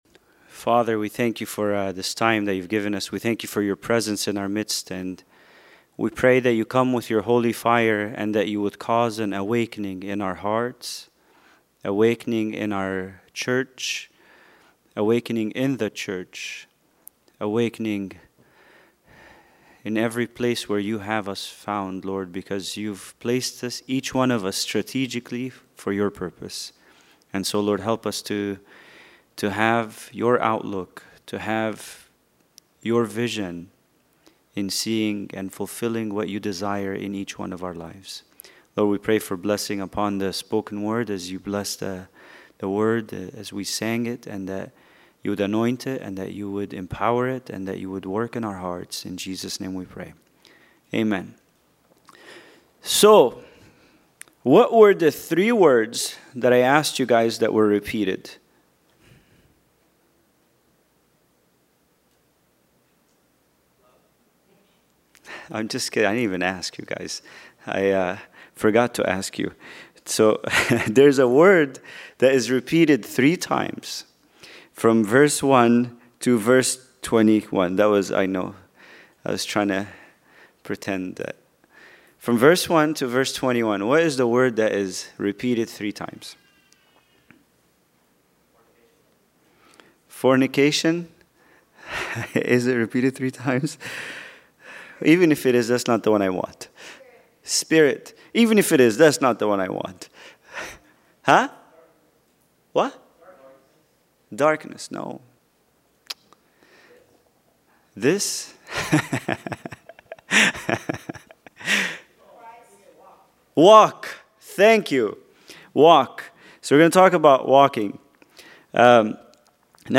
Bible Study: Ephesians 5:1-2